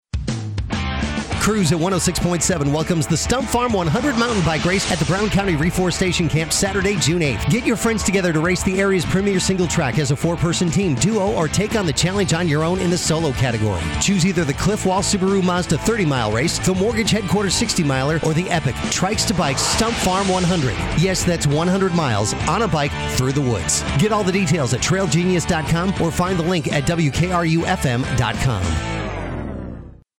Check out the Stump Farm 100 promo playing on the radio, with a big shout out to Trail Genius!